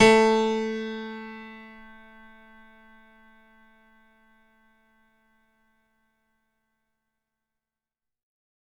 55ay-pno08-a2.wav